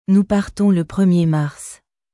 Nous partons le premier marsヌゥ パァルトン ル プルミエ マァルス